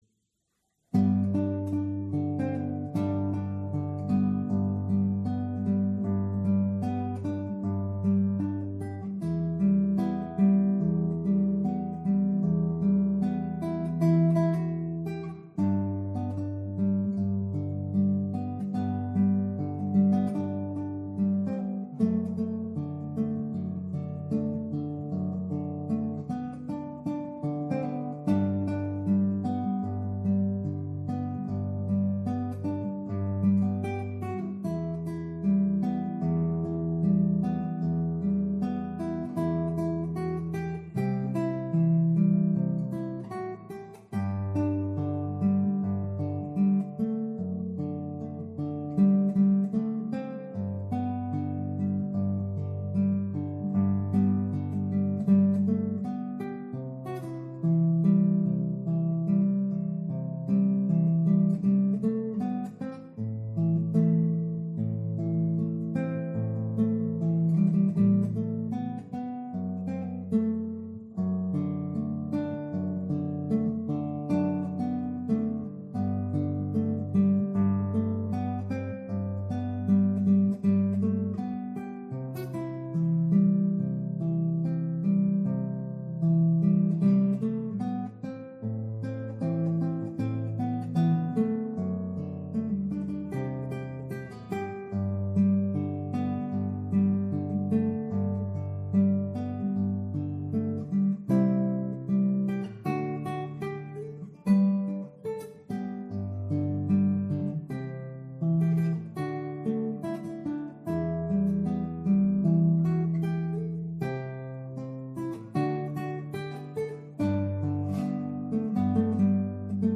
Standard Tuning - 4/4 Time